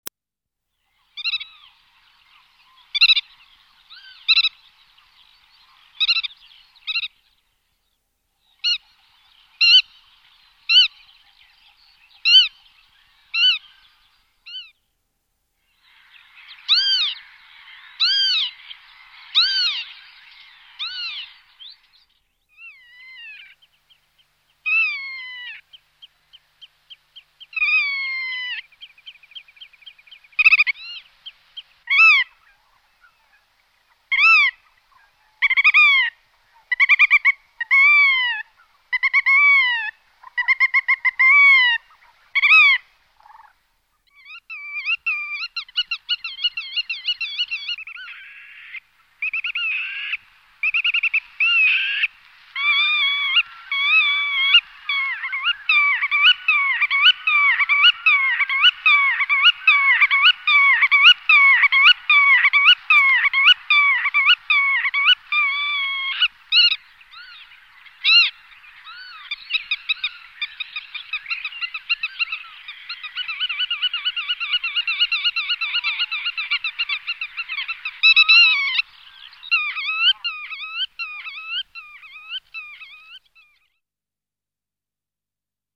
Они агрессивно трепещут в воздухе, изогнув крылья, и машут ими то, взлетая, то  садясь, облетая чужака, и издавая громкие, высокие, даже, визгливые крики.